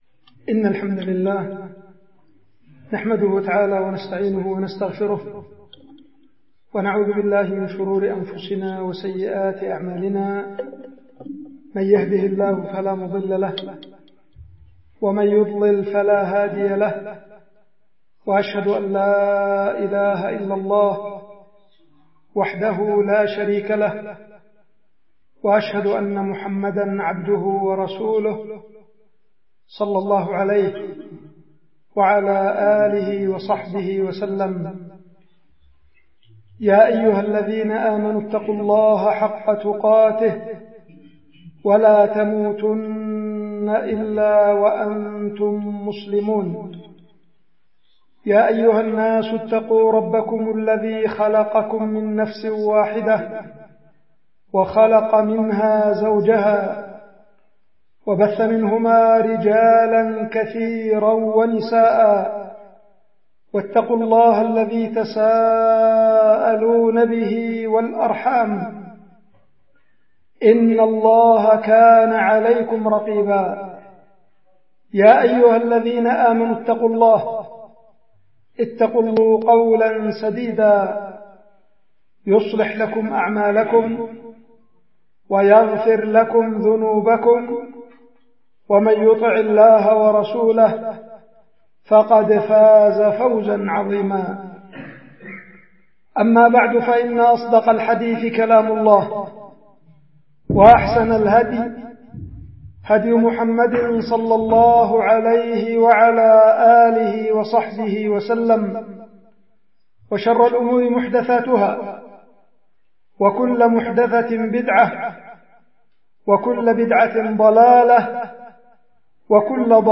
خطبة
القيت في مسجد قرية المحاط مديرية السبرة محافظة إب